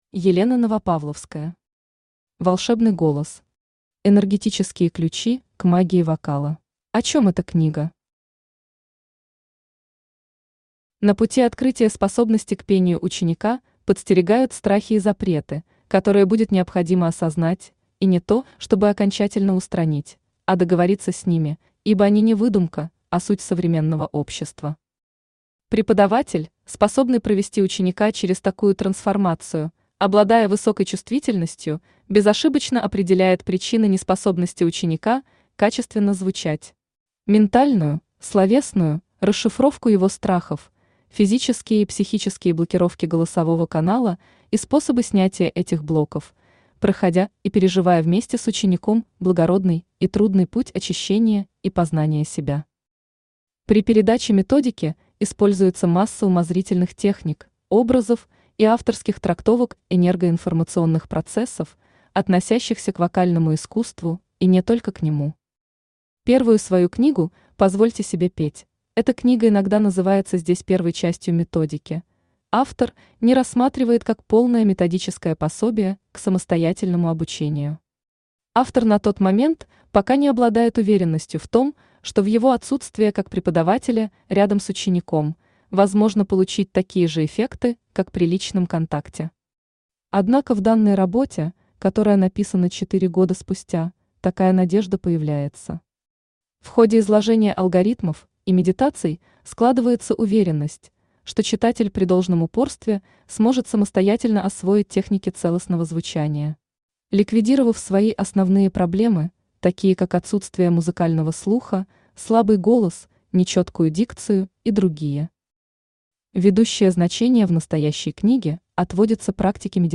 Аудиокнига Волшебный голос.
Aудиокнига Волшебный голос. Энергетические ключи к магии вокала Автор Елена Новопавловская Читает аудиокнигу Авточтец ЛитРес.